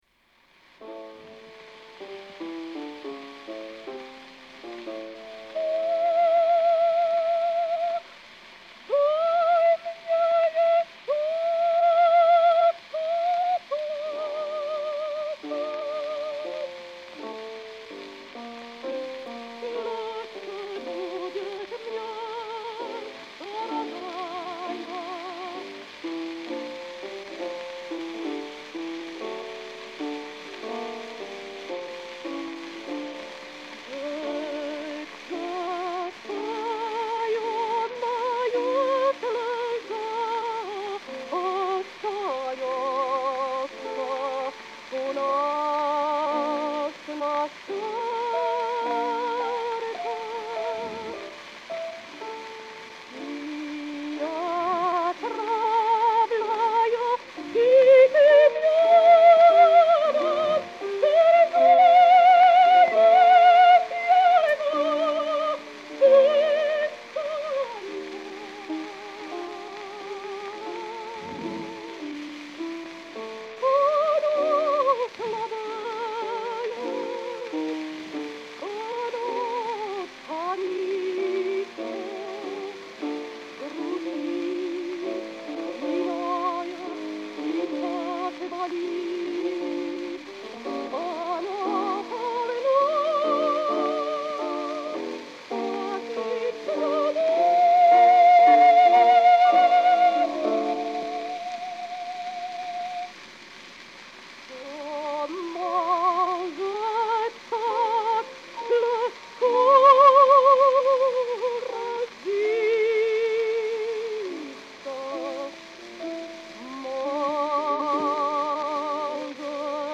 A l’ària de les llàgrimes, la línia melòdica és ondulant i descendent per mostrar el plor de la protagonista.
1 Medea Mei-Figner 1901 “Va! Laisse couler les larmes” acom panyada a piano i cantada en rus